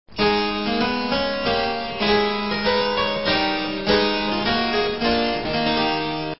harpsichord to hear an example of an instrument
harp.wav